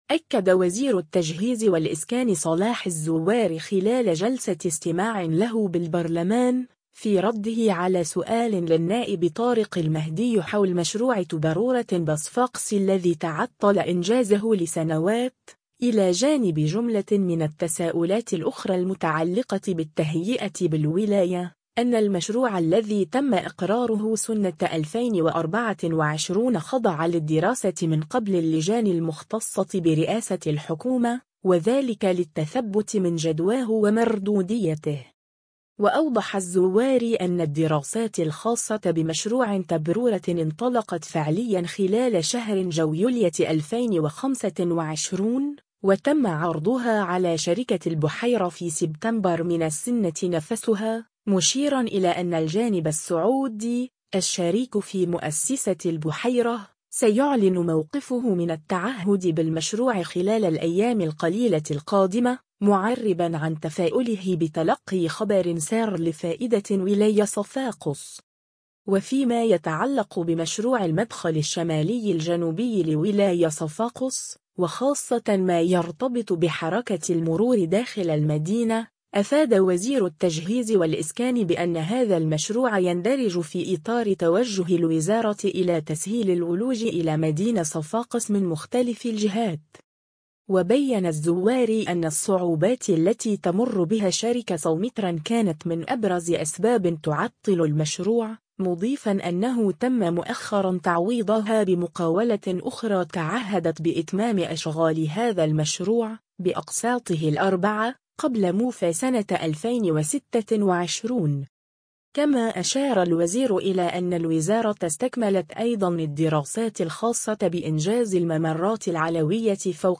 أكد وزير التجهيز والإسكان صلاح الزواري خلال جلسة استماع له بالبرلمان،، في رده على سؤال للنائب طارق المهدي حول مشروع تبرورة بصفاقس الذي تعطل إنجازه لسنوات، إلى جانب جملة من التساؤلات الأخرى المتعلقة بالتهيئة بالولاية، أن المشروع الذي تم إقراره سنة 2024 خضع للدراسة من قبل اللجان المختصة برئاسة الحكومة، وذلك للتثبت من جدواه ومردوديته.